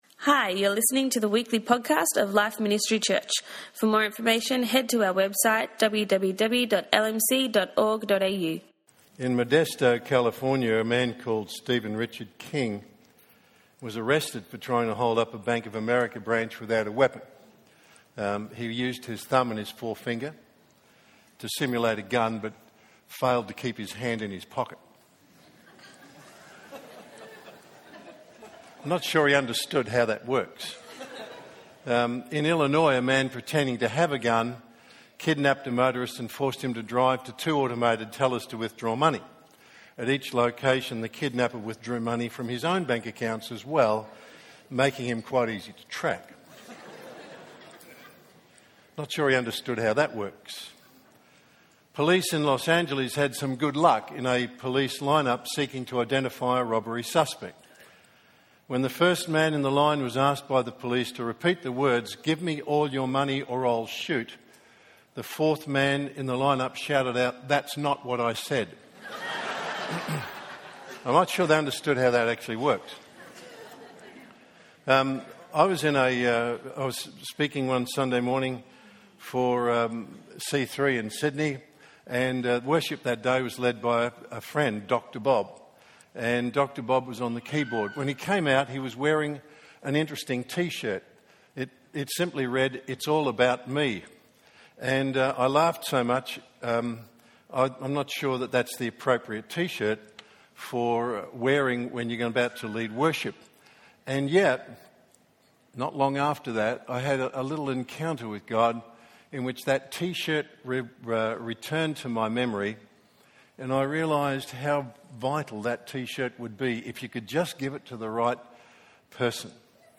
In the third and final message of his series 'The Messiah'